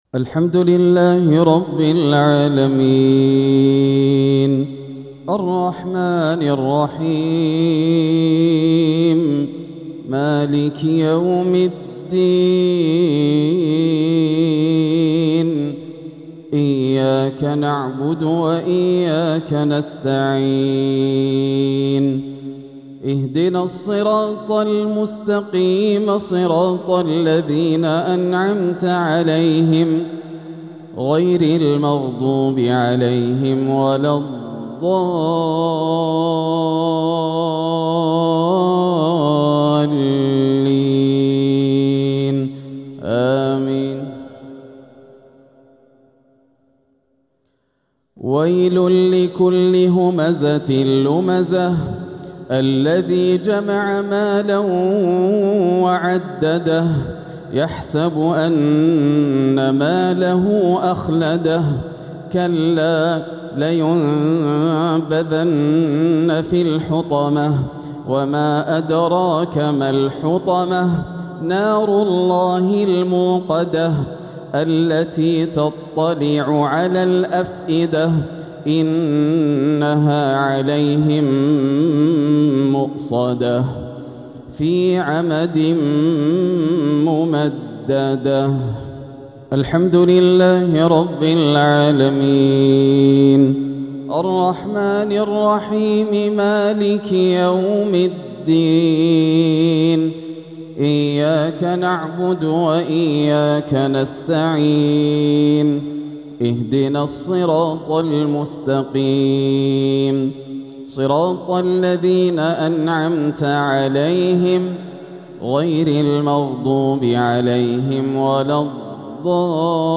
سورتي الهمزة والناس | مغرب الاثنين 3-6-1447هـ > عام 1447 > الفروض - تلاوات ياسر الدوسري